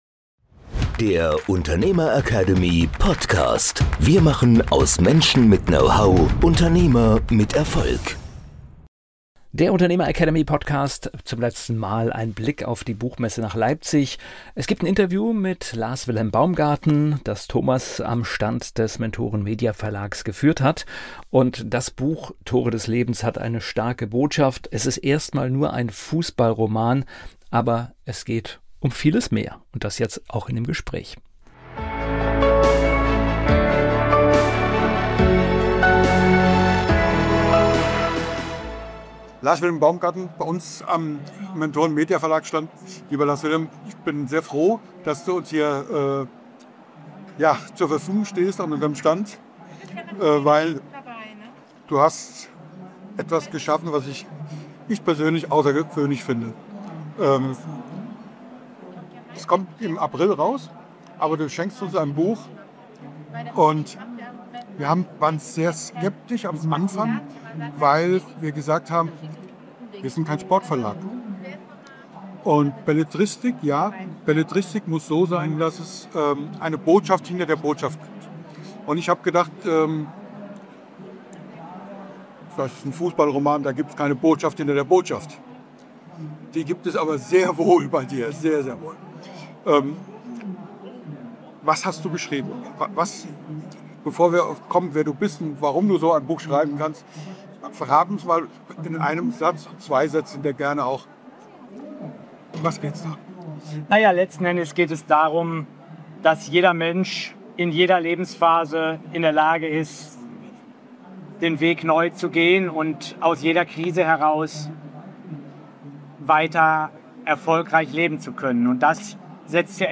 In dieser Folge des Unternehmer Academy Podcasts werfen wir einen exklusiven Blick auf die Leipziger Buchmesse.